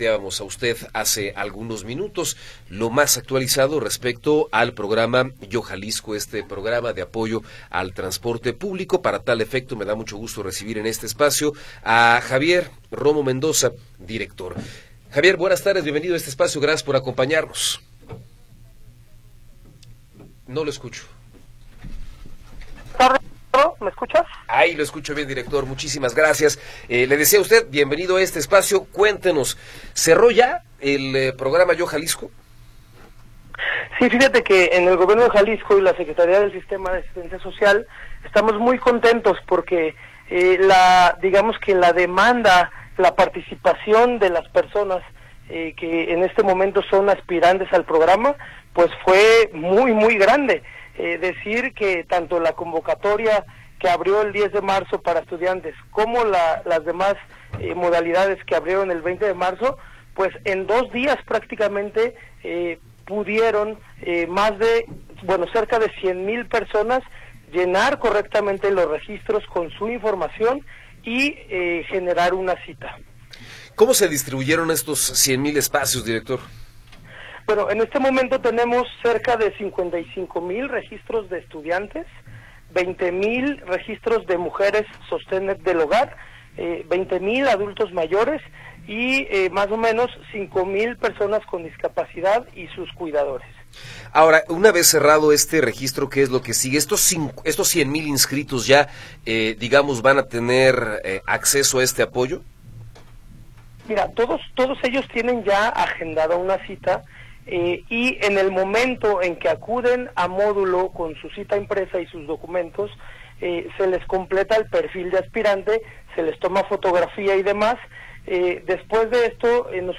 Entrevista con Javier Romo Mendoza